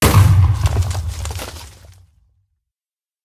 explode4.ogg